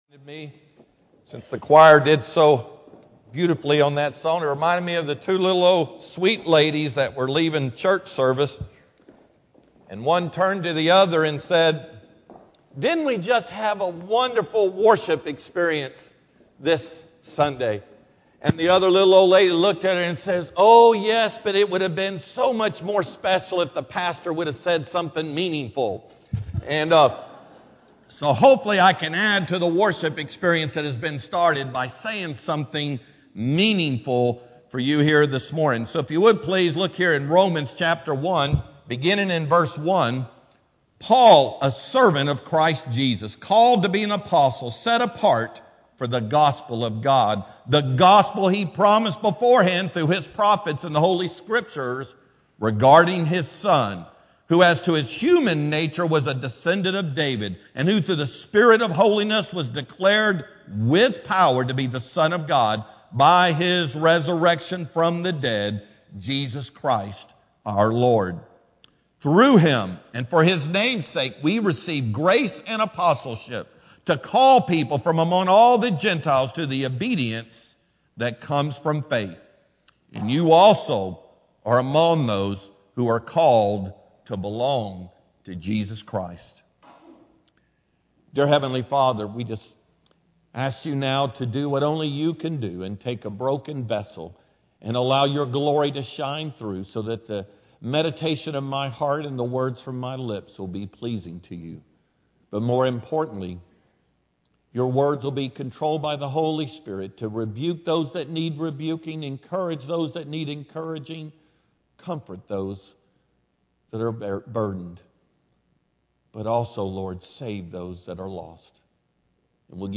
Sermon-2-22-AM-CD.mp3